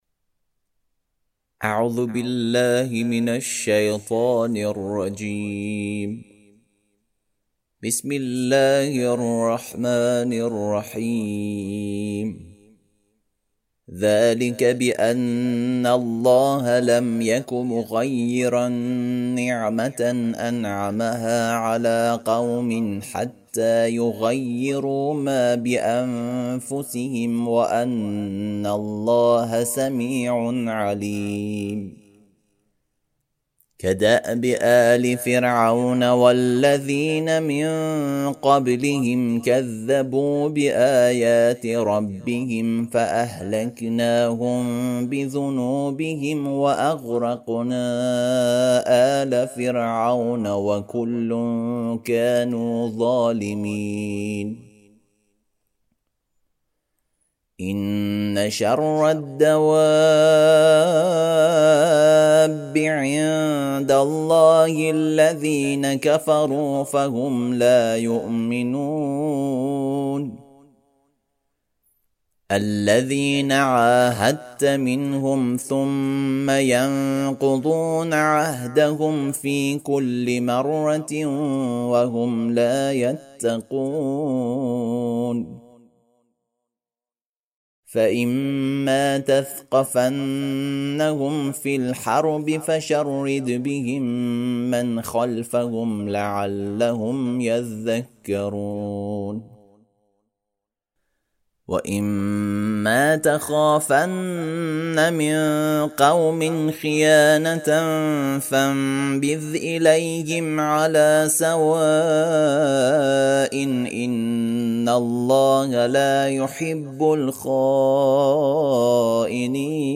ترتیل صفحه ۱۸۴ سوره مبارکه انفال(جزء دهم)
ترتیل سوره(انفال)